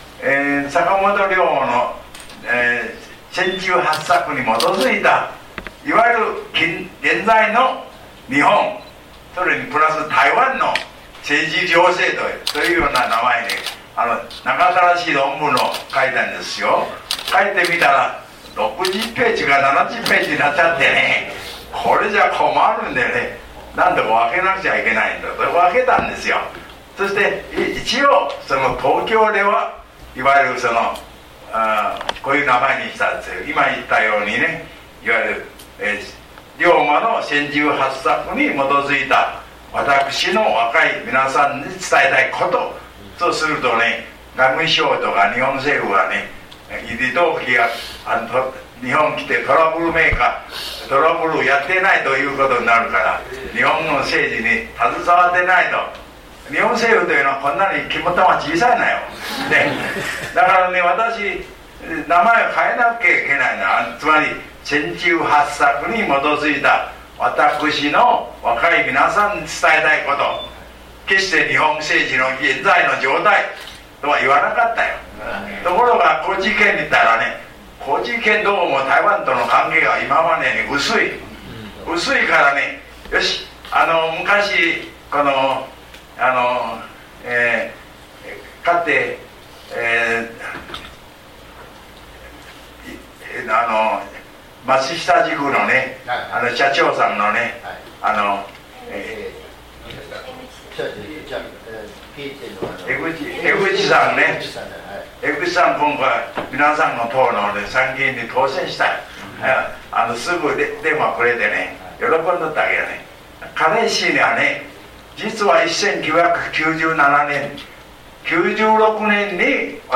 〈ダウンロードはこちらをクリック↓〉 坂本龍馬の『船中八策』に基づいて、李登輝元総統が日本人へ伝えたいこと 日時：2010年7月21日 場所：台湾淡水、群策会（のちの李登輝基金会）会議室 時間：約40分 ※10年以上前の音源のため、一部聞き取りにくいところがございます。